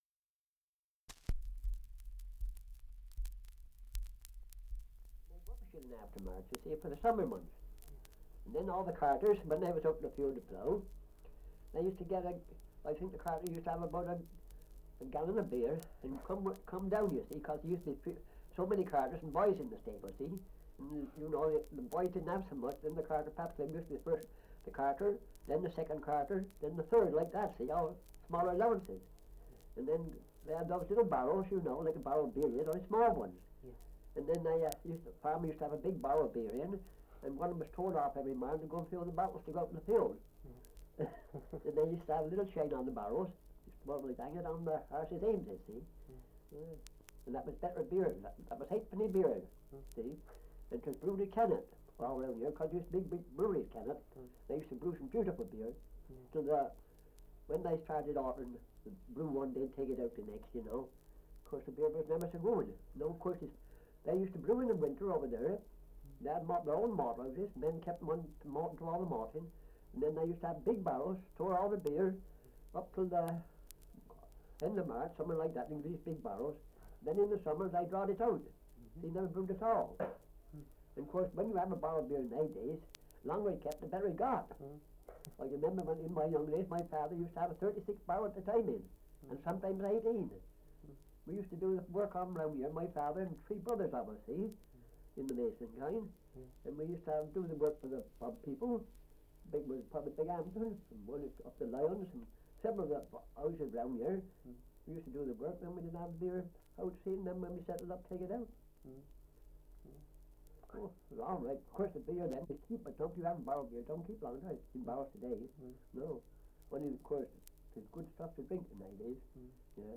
Survey of English Dialects recording in Avebury, Wiltshire
78 r.p.m., cellulose nitrate on aluminium